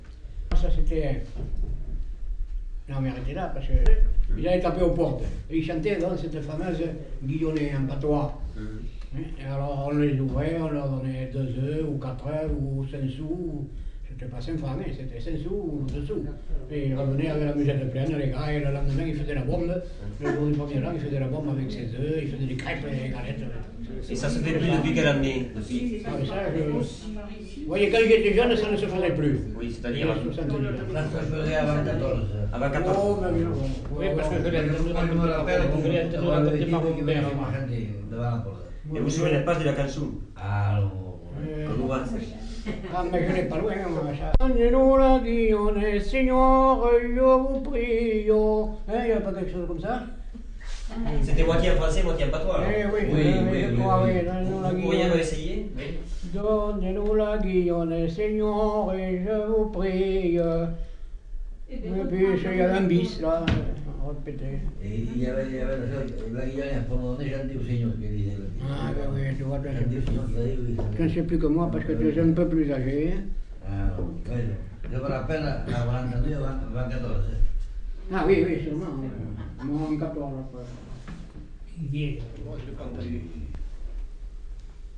enquêtes sonores